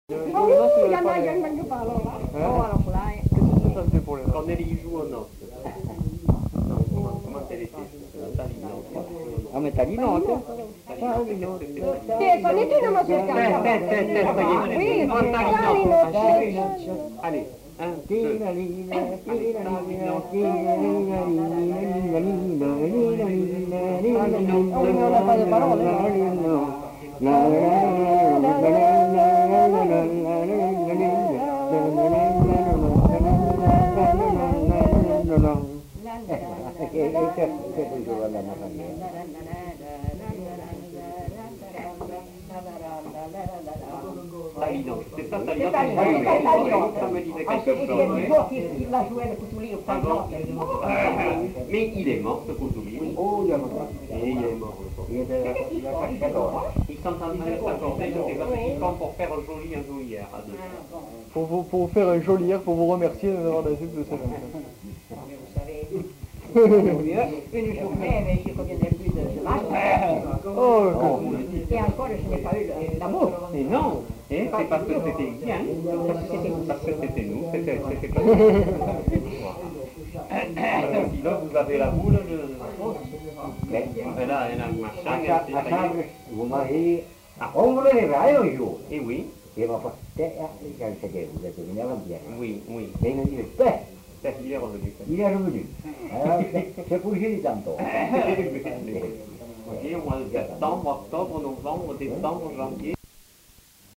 Aire culturelle : Grandes-Landes
Lieu : Luxey
Genre : chant
Type de voix : voix mixtes
Production du son : fredonné
Danse : mazurka